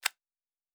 pgs/Assets/Audio/Sci-Fi Sounds/Interface/Click 12.wav at 7452e70b8c5ad2f7daae623e1a952eb18c9caab4
Click 12.wav